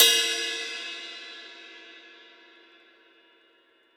• Ride F Key 07.wav
Royality free ride single hit tuned to the F note.
ride-f-key-07-WPY.wav